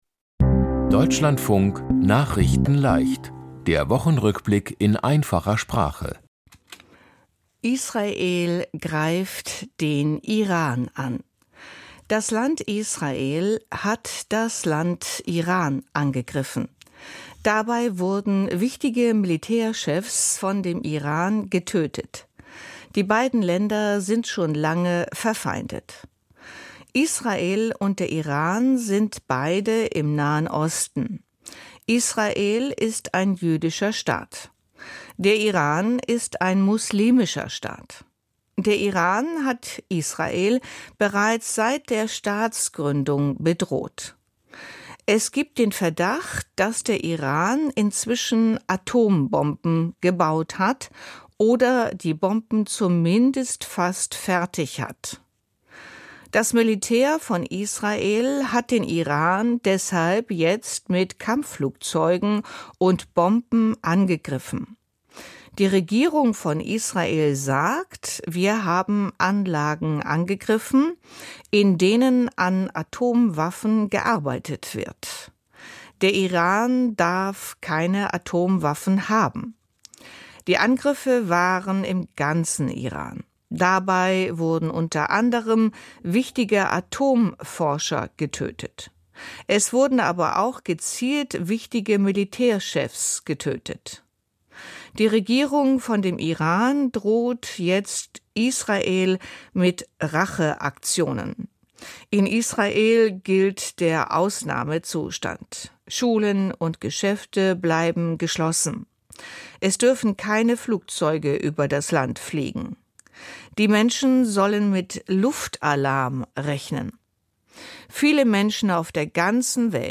Die Themen diese Woche: Israel greift den Iran an, Proteste in Los Angeles – Trump schickt Soldaten, Flugzeug in Indien abgestürzt, Tote bei Amok-Lauf an Schule in Österreich, Bahn macht reservierte Plätze für Familien teurer und Kunst-Aktion erinnert an Reichstags-Verhüllung. nachrichtenleicht - der Wochenrückblick in einfacher Sprache.